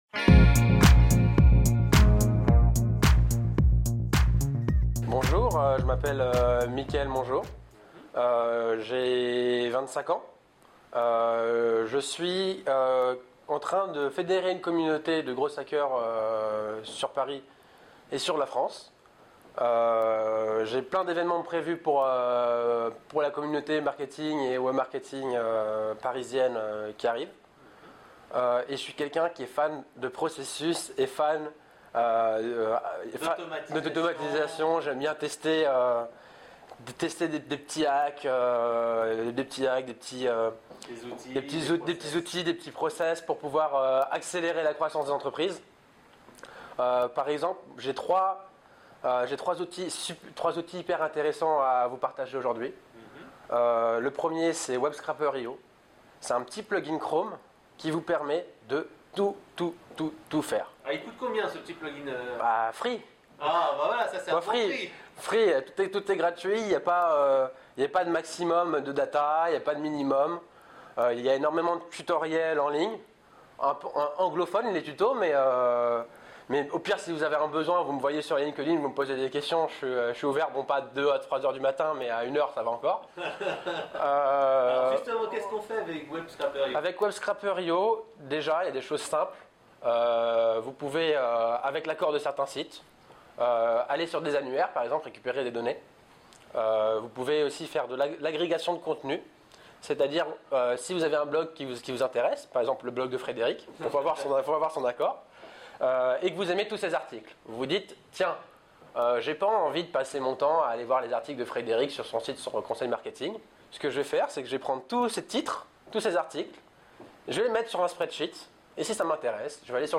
3 outils de Growth Hacking - Interview